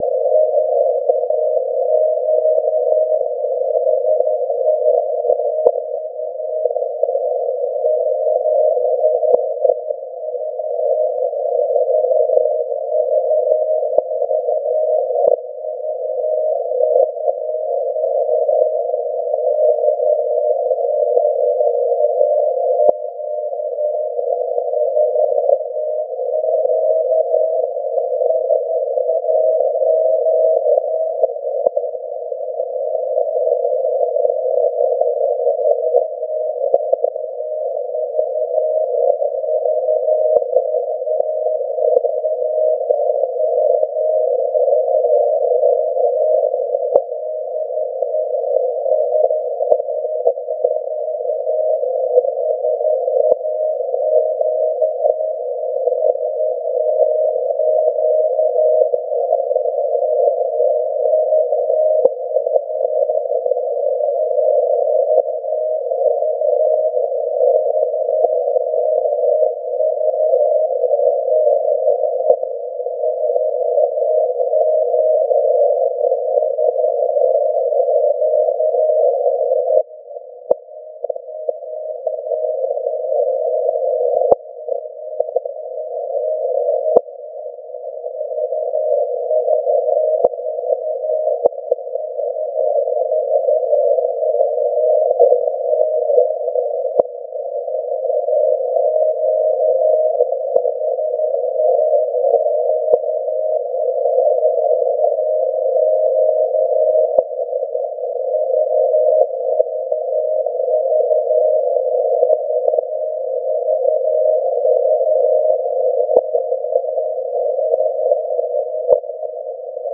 La stazione di Grimeton e’ l’unico esemplare di stazione CW tuttora funzionante in onde lunghissime e nel 2004 e’ stata dichiarata “Patrimonio dell’Umanita’” dall UNESCO.
Quella che voglio raccontarvi e’ la ricezione da me effettuata proprio in questa occasione.